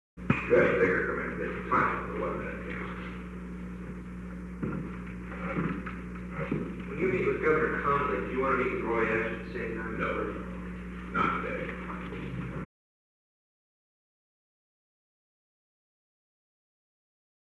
Conversation: 919-012
Recording Device: Oval Office
The Oval Office taping system captured this recording, which is known as Conversation 919-012 of the White House Tapes.
The President met with an unknown man.